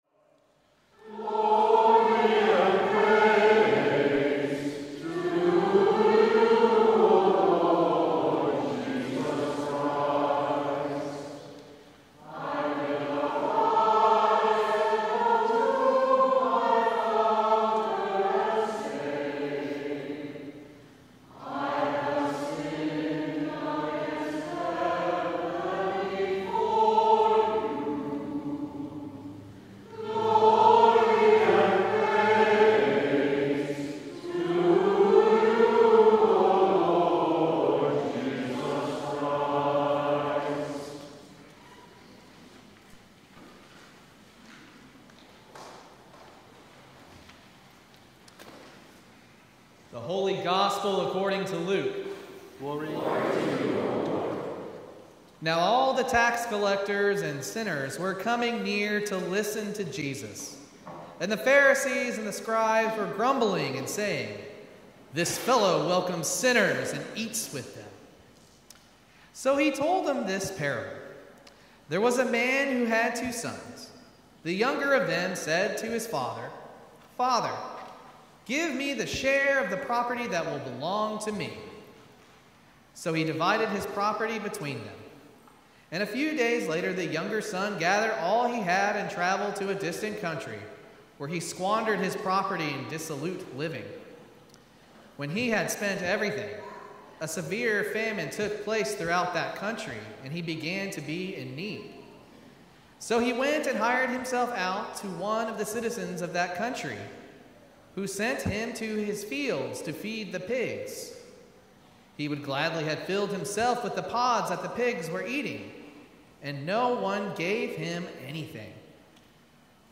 Sermon from the Fourth Sunday in Lent